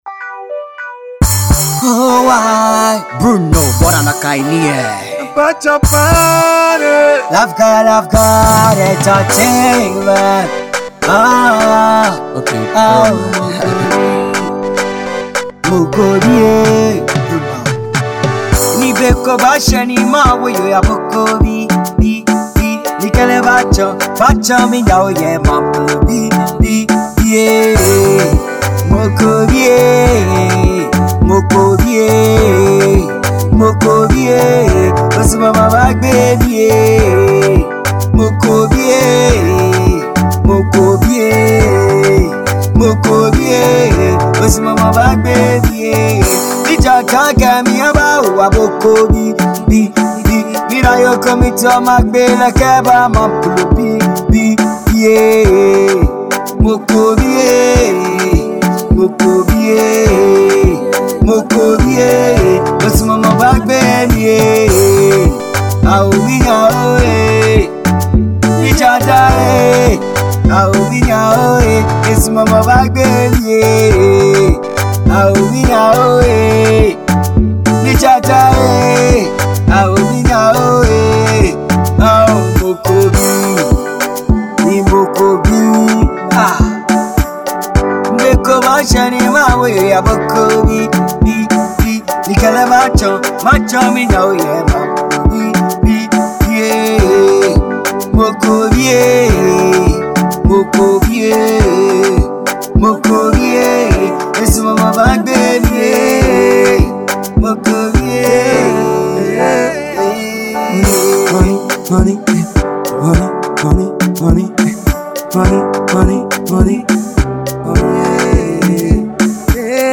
Xmas banger
Afro-pop song